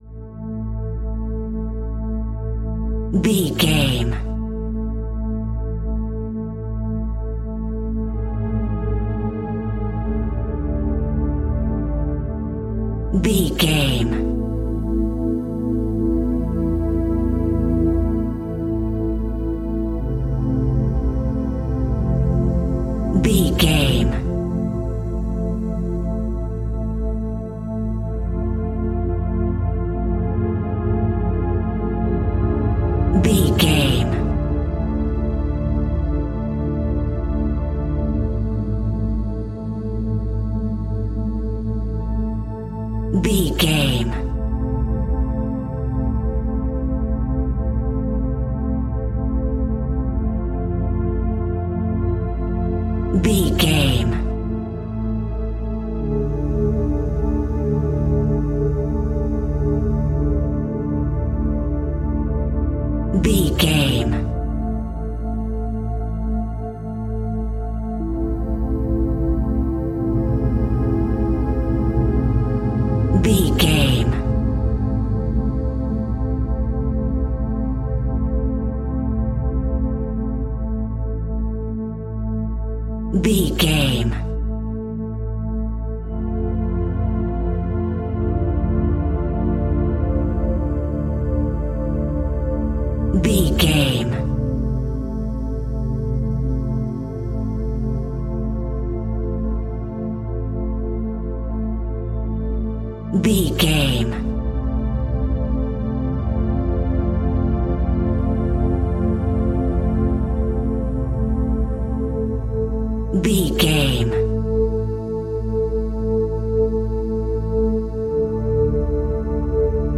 Goblin Music.
Aeolian/Minor
SEAMLESS LOOPING?
ominous
dark
suspense
eerie
strings
synthesiser
ambience
pads